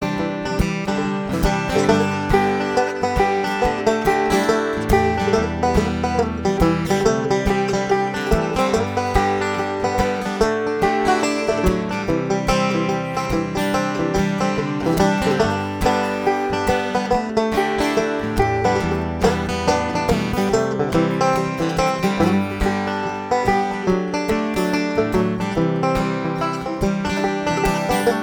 Live Audio - Duo - (guitar and banjo)